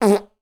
little parp
anus egg fart trump sound effect free sound royalty free Memes